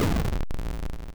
lose.wav